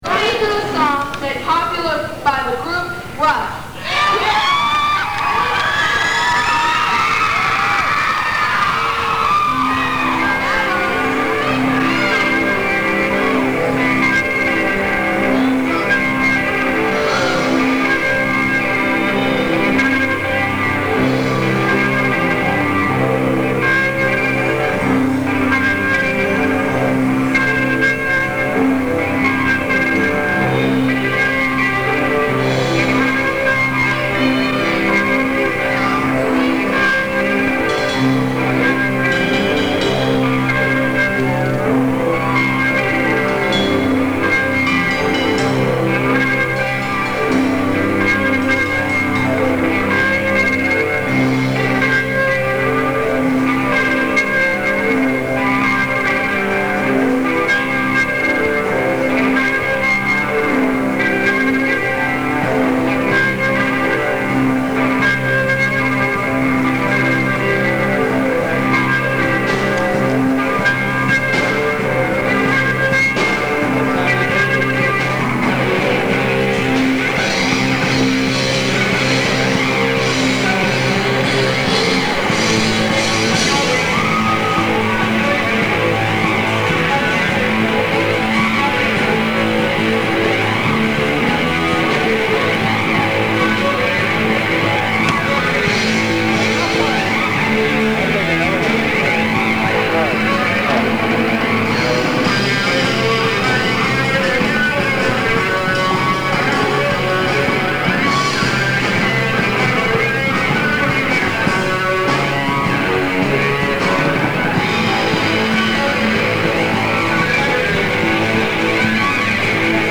talent show